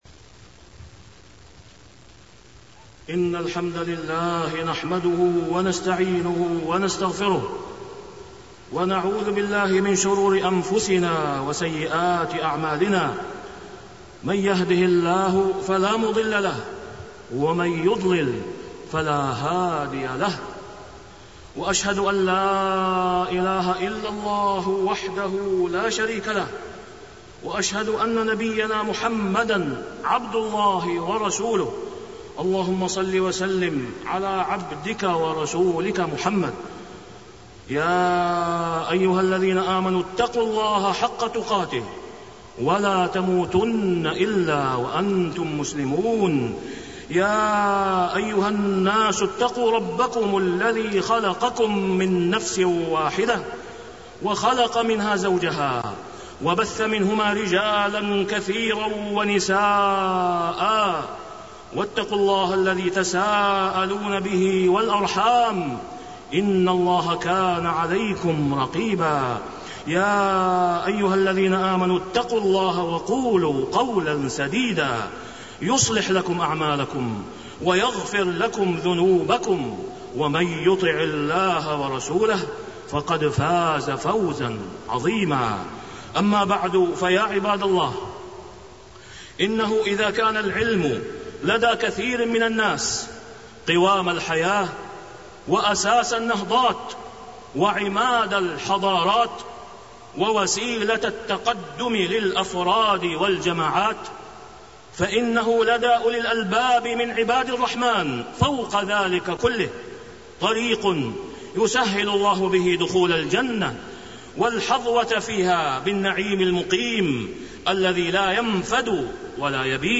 تاريخ النشر ٢٥ شعبان ١٤٢٨ هـ المكان: المسجد الحرام الشيخ: فضيلة الشيخ د. أسامة بن عبدالله خياط فضيلة الشيخ د. أسامة بن عبدالله خياط فضل العلم واستقبال رمضان